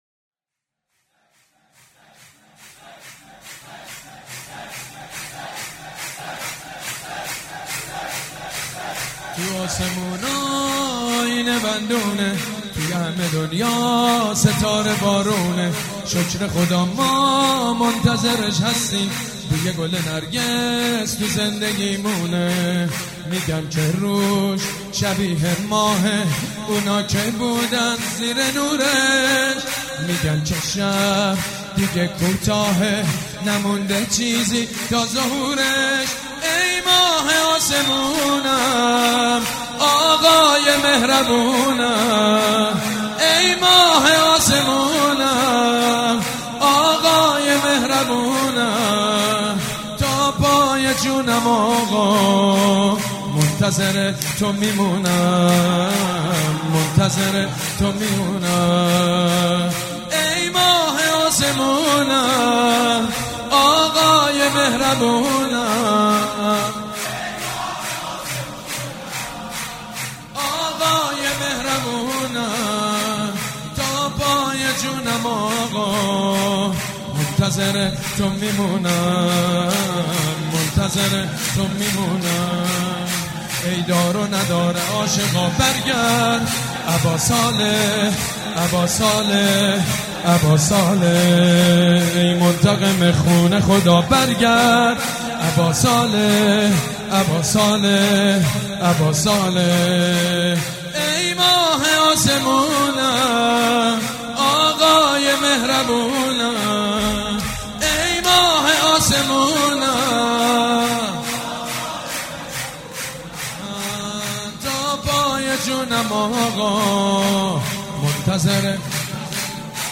روضه
حاج سید مجید بنی فاطمه
جشن نیمه شعبان
شور1.mp3